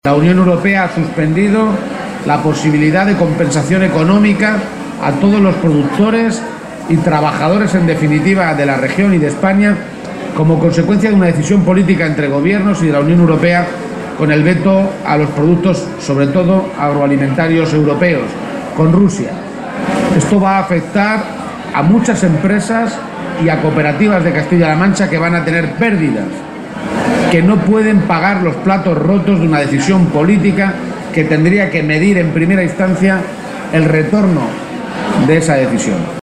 Page se pronunciaba de esta manera en su comparecencia ante los medios de comunicación, en Albacete, pocos minutos antes del acto de entrega de los Premios Pablo Iglesias que otorga la agrupación local socialista de la capital albaceteña.